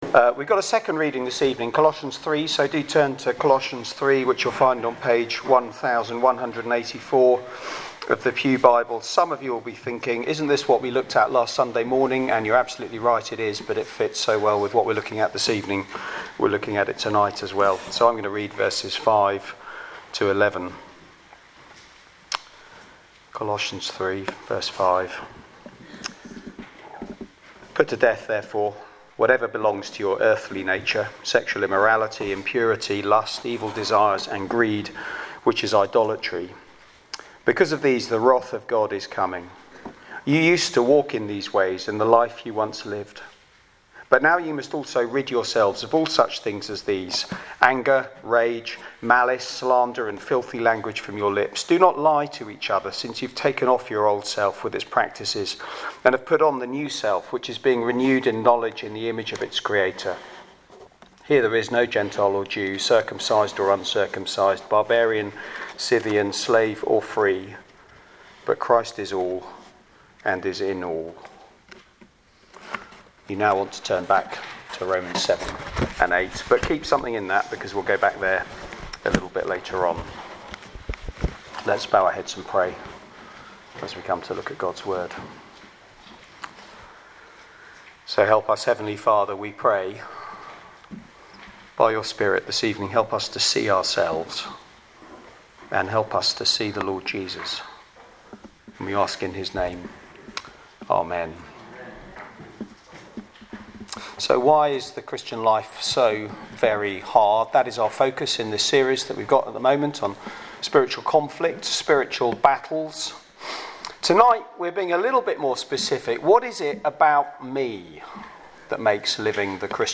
Media for Sunday Evening
Series: Spiritual battle Theme: Sermon